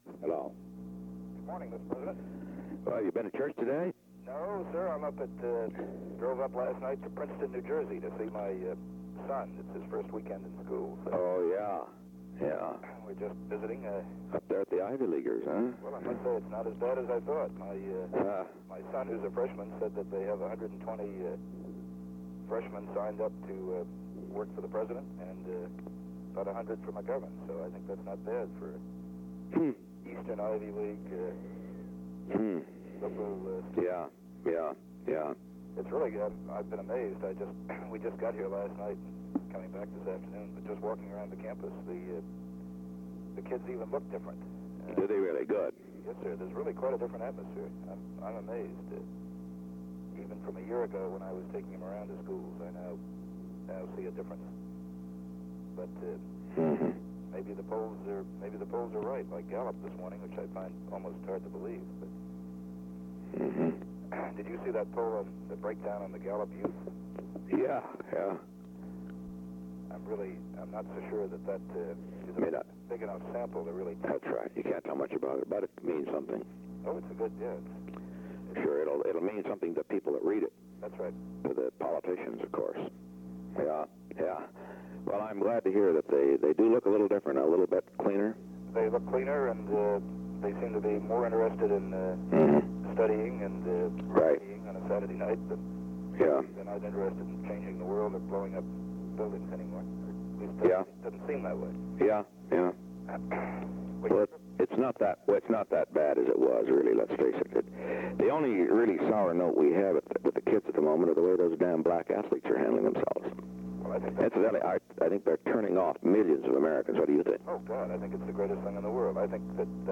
The Presidency / Featured Content 'Those Damn Black Athletes' 'Those Damn Black Athletes' Photo: United States Postal Service After reviewing the political climate on college campuses, President Nixon and presidential aide Charles W. Colson discussed the suspension of athletes Vincent Matthews and Wayne Collette from the Olympic Games in Munich.
Colson thought the affair would benefit the President politically. Date: September 10, 1972 Location: Camp David Study Table Tape Number: 141-002 Participants Richard M. Nixon Charles W. “Chuck” Colson Associated Resources Audio File Transcript